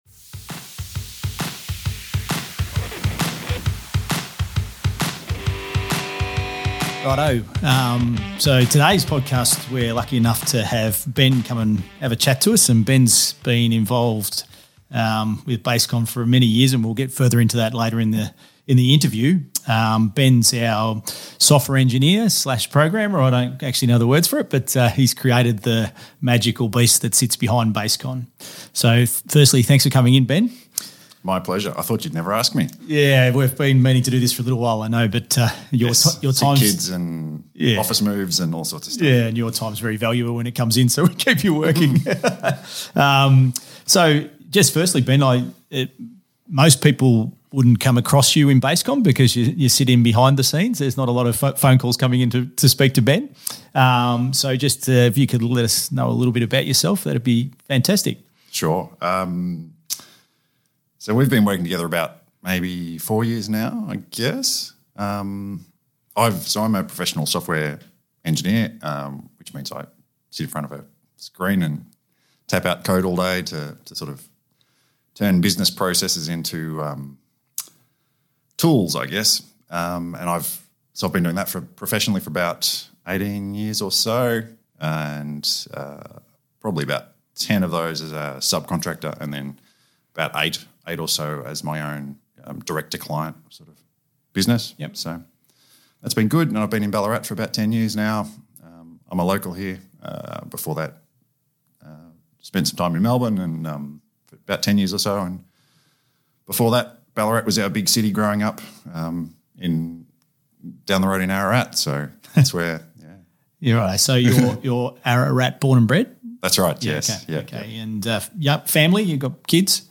Interview on The Construction Game podcast